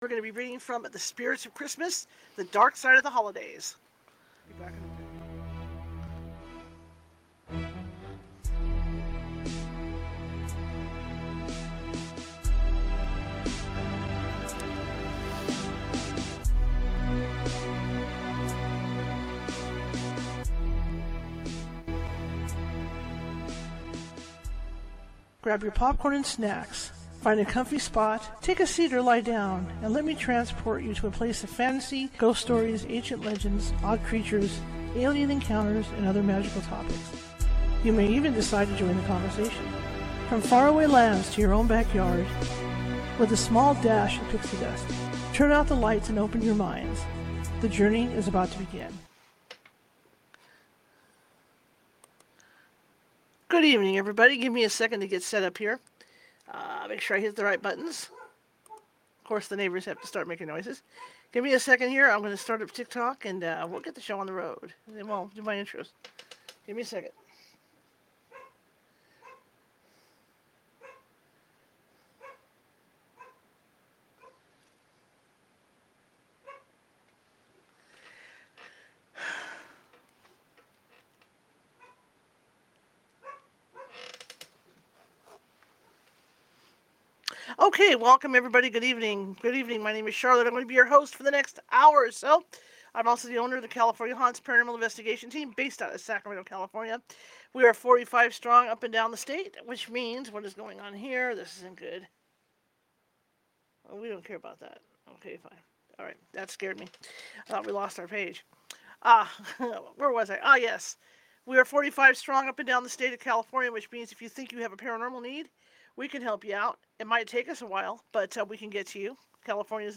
Permission to read book online granted by the author/publisher